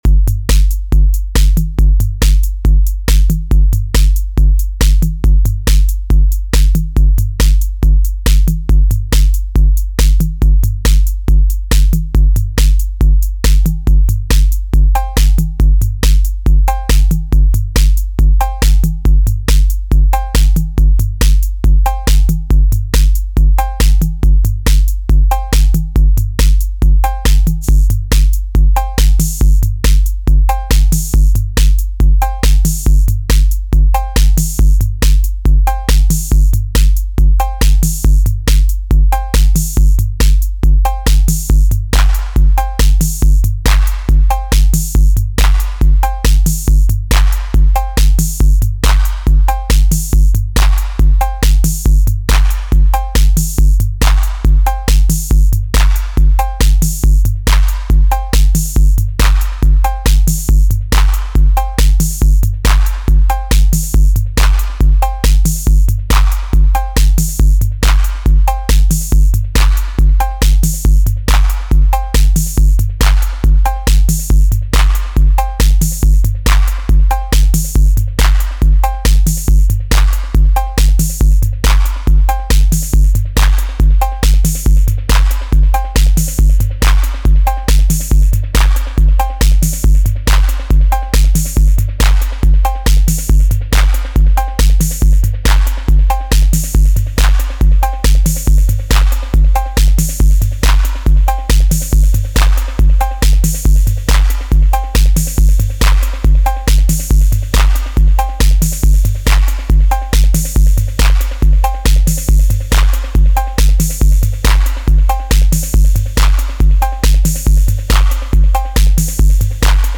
Here’s a “box sound” example of an analog 808 kit, master delay and reverb, master compression and analog drive. Pitched up to fit under 10mb :slight_smile: